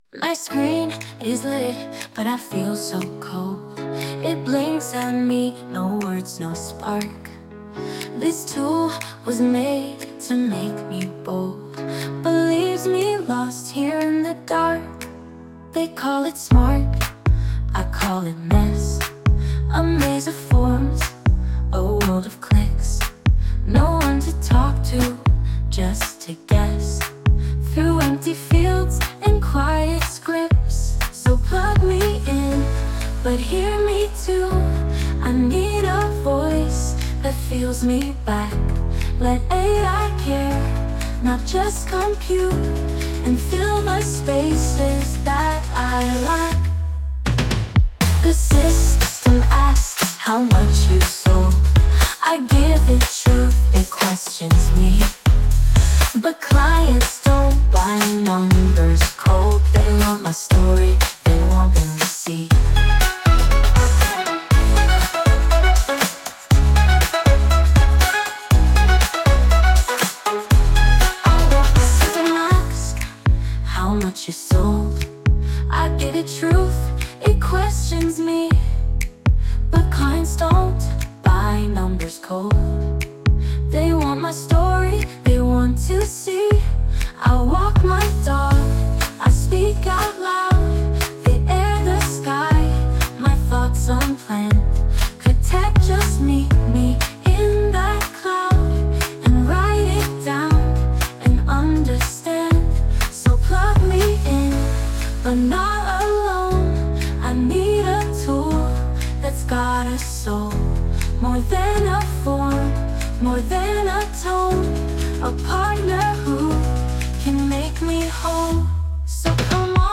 Dit lied is volledig met AI gegenereerd. De teksten zijn afkomstig van de interviews van aflevering 7.